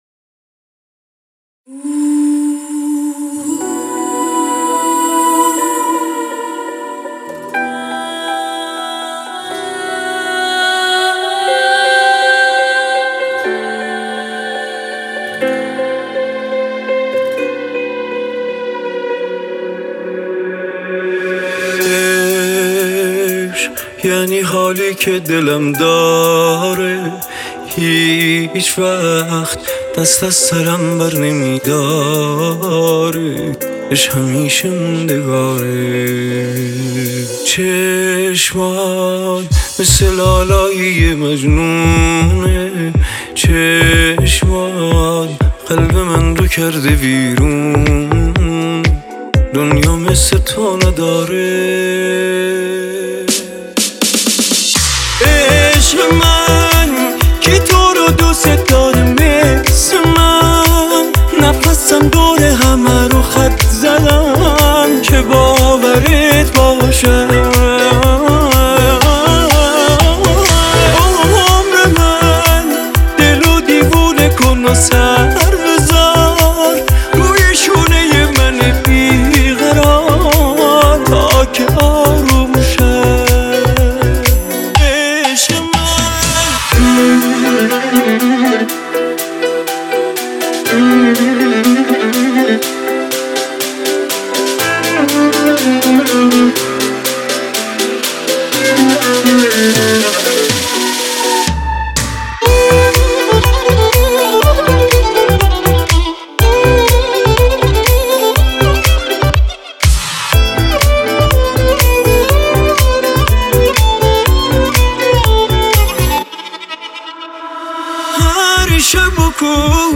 آهنگ غمگین عشق من کی تو رو دوست داره مثل من